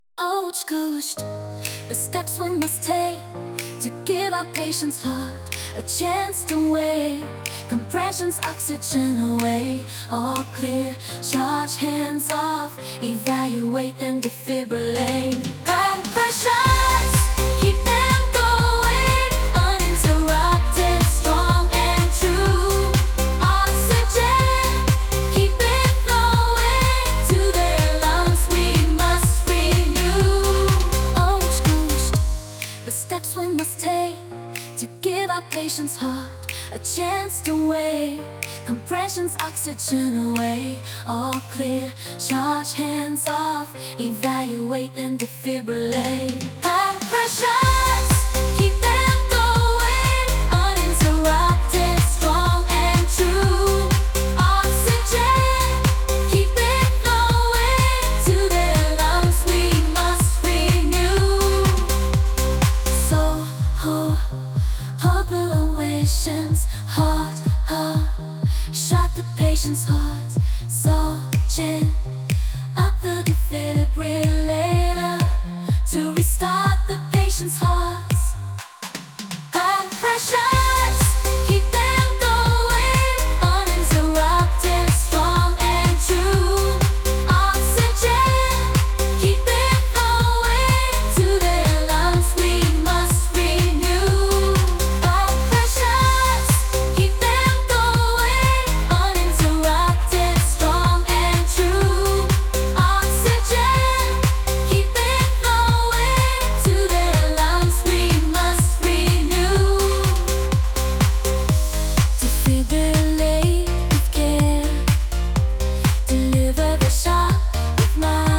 Coached female house.mp3